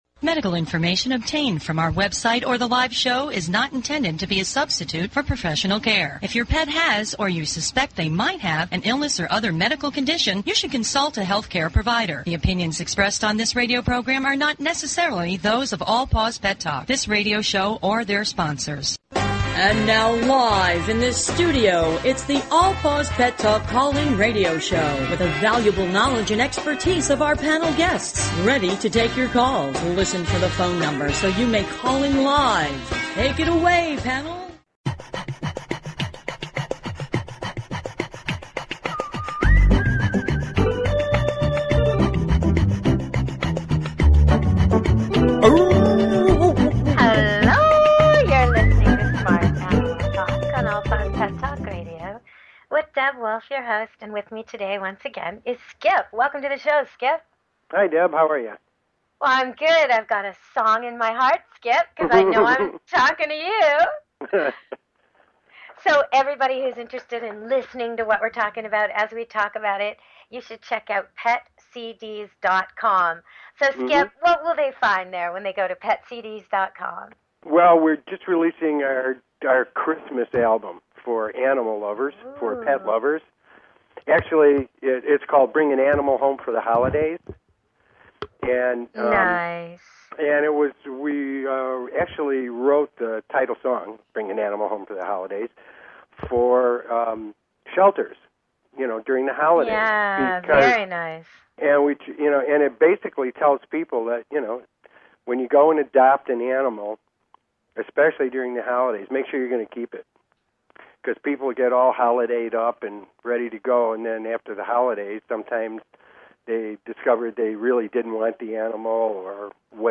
Talk Show Episode, Audio Podcast, All_Paws_Pet_Talk and Courtesy of BBS Radio on , show guests , about , categorized as
All Paws Pet Talk is directed to the millions of owners who are devoted to their pets and animals. Our hosts are animal industry professionals covering various specialty topics and giving free pet behavior and medical advice. We give listeners the opportunity to speak with animal experts one on one.